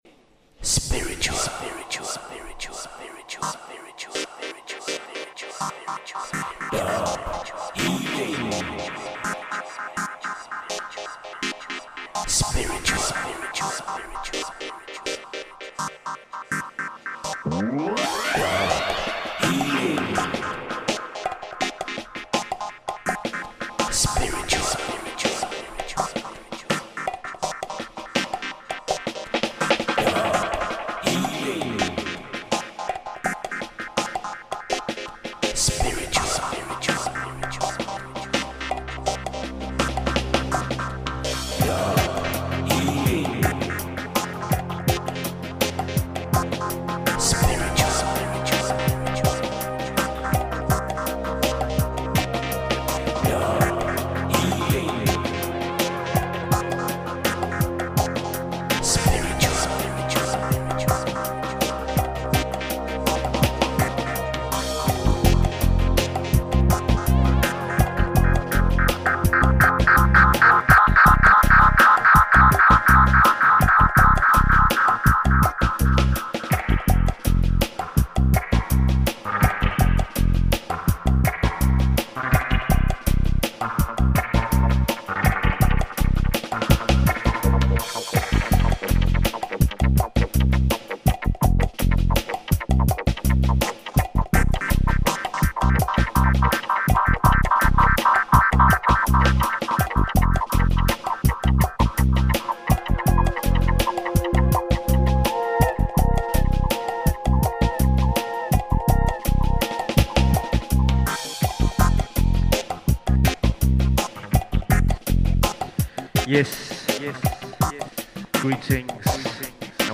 The monthly live dub mixing show
streamed directly out of Copenhagen, Denmark.
from the very sweet to the very hard style.